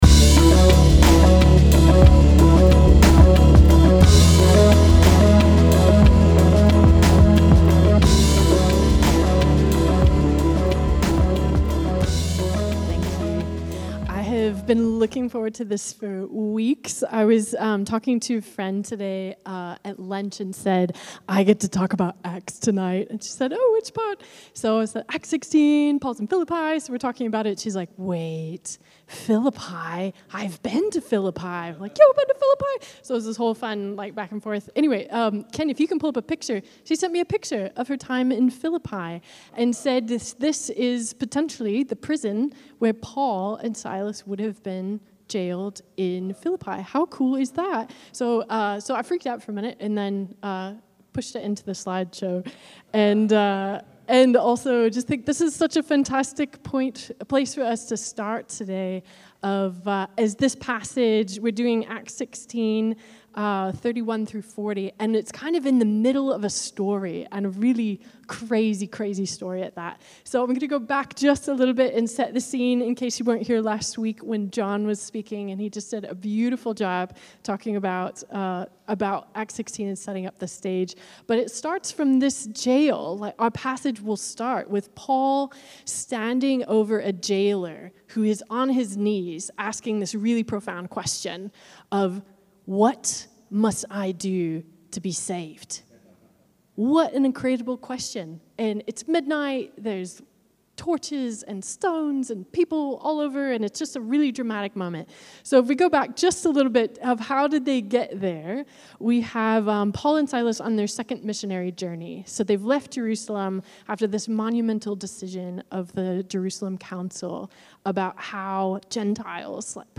At our Sunday at 6pm service